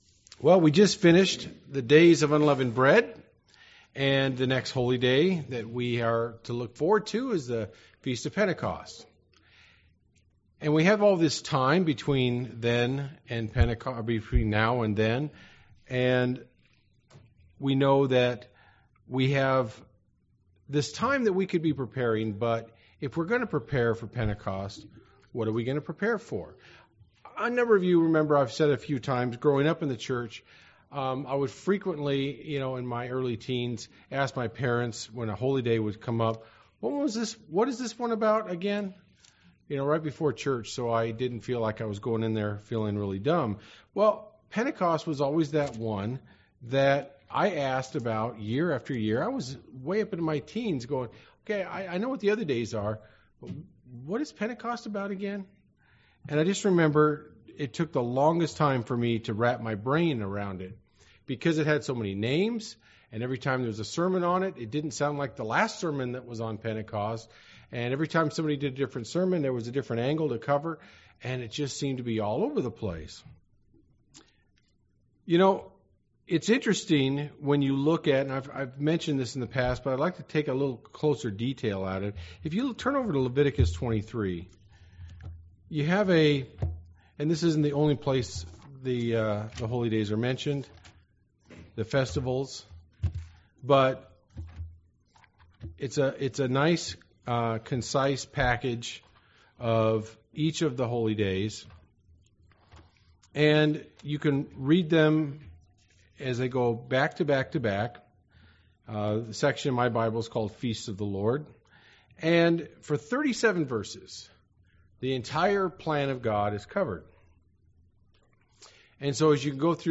Every Holy Day has a meaning, but what is that of Pentecost? In this sermon